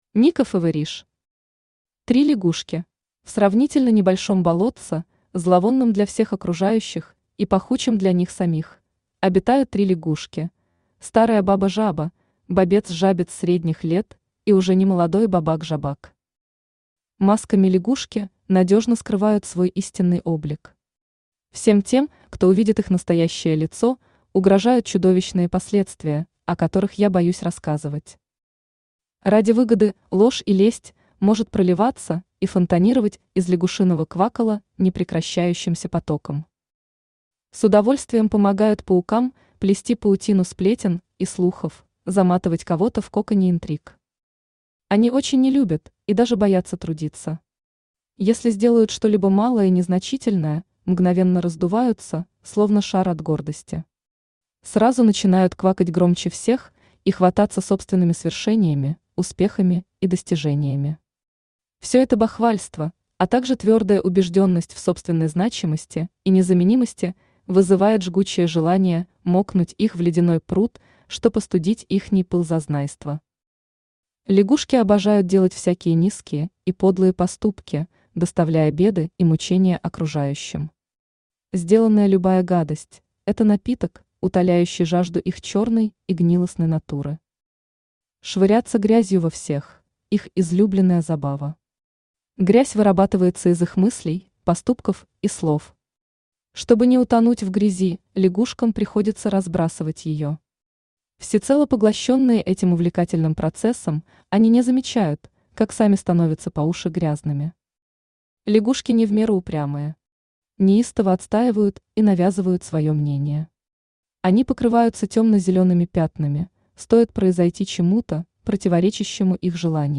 Aудиокнига Три лягушки Автор Ника Фэвэриш Читает аудиокнигу Авточтец ЛитРес.